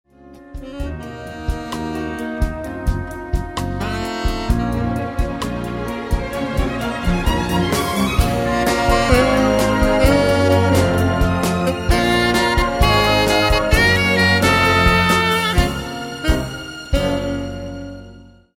Saxophon und Klavier